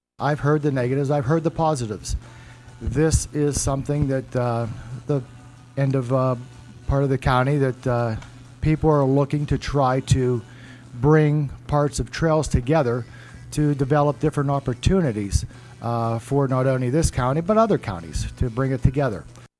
This morning on WCCS AM 1160 and 101.1 FM, two debates were held concerning two positions of county government that are on the ballot for next week’s election.